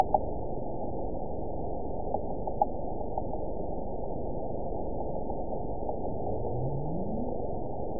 event 911563 date 03/03/22 time 15:44:50 GMT (3 years, 2 months ago) score 9.49 location TSS-AB05 detected by nrw target species NRW annotations +NRW Spectrogram: Frequency (kHz) vs. Time (s) audio not available .wav